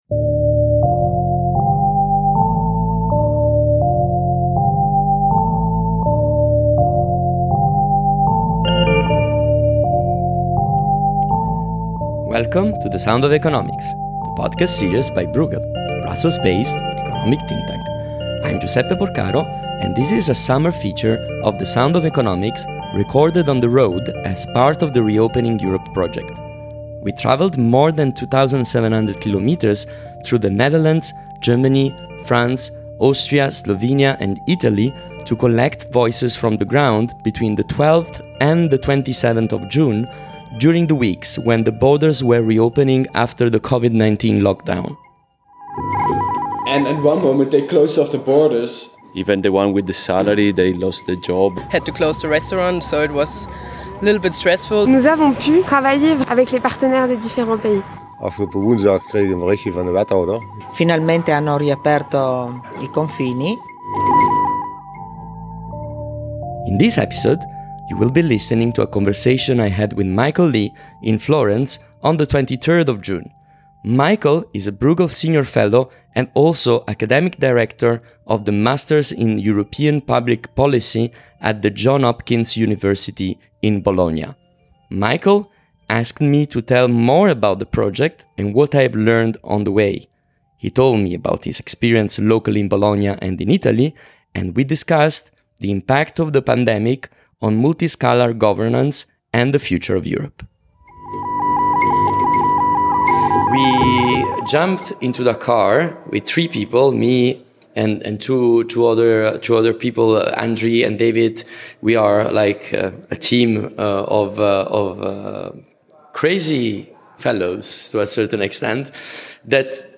Their conversation was recorded in Florence on the 23rd of June.